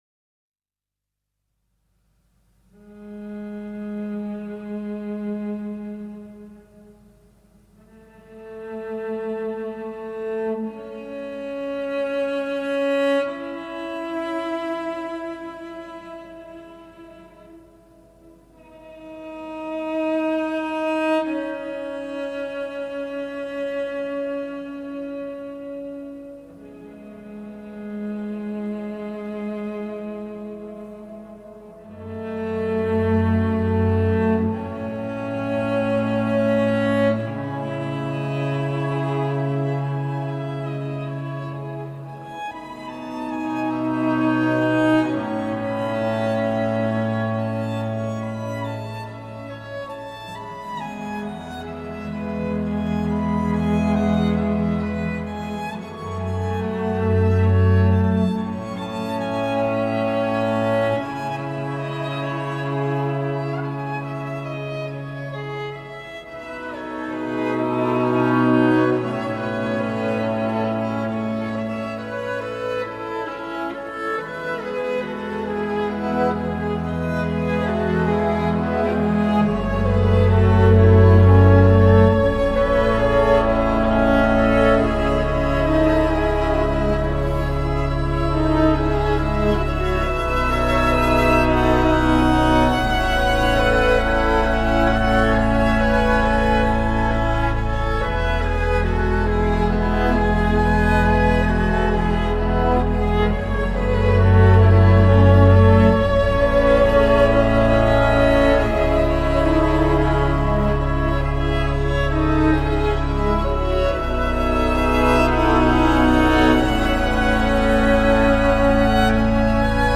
Neo-Classical, Contemporary Classical